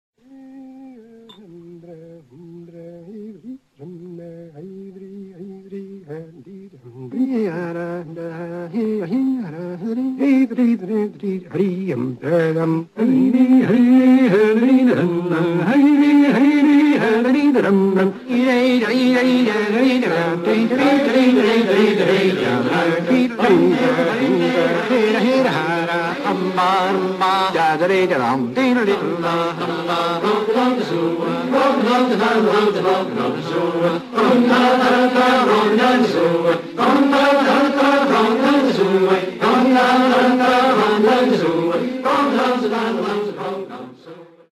영화에서 불린 ''퓌르슈트 아 뷜/puirt à beulgd'' 음악